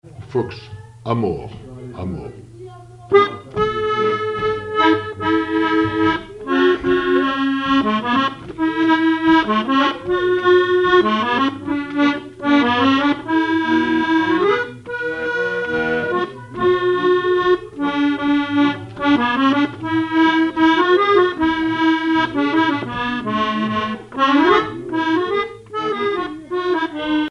accordéon(s), accordéoniste
danse : fox-trot
Répertoire à l'accordéon chromatique
Pièce musicale inédite